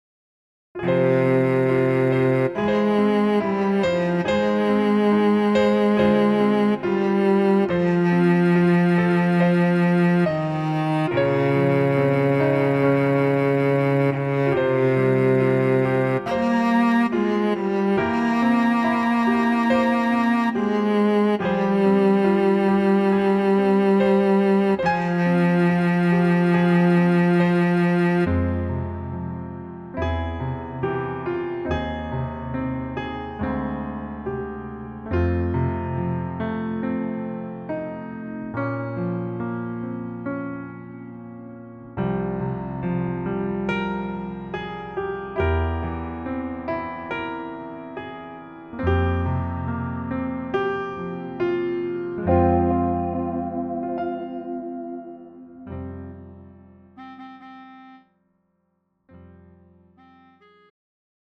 음정 남자키
장르 가요 구분 Pro MR